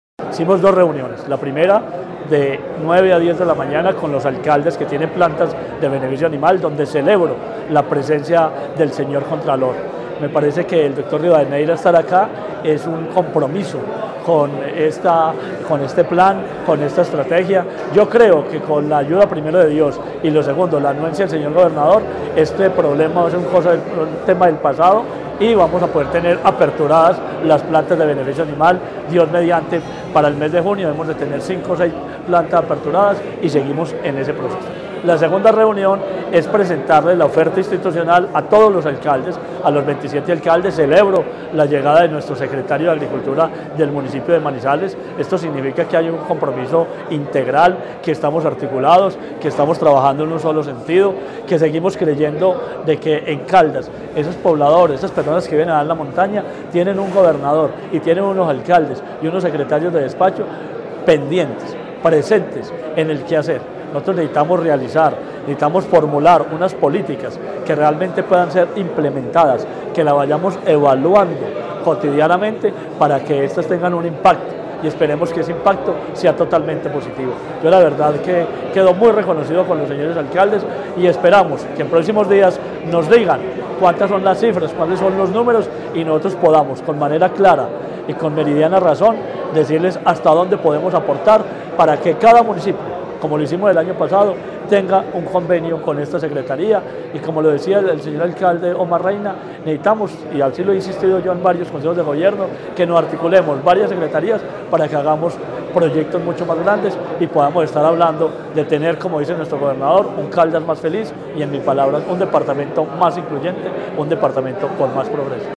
Marino Murillo, secretario de Agricultura de Caldas.